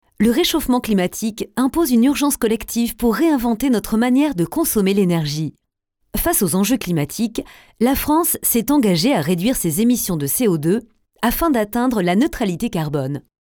Commerciale, Naturelle, Enjouée, Polyvalente, Corporative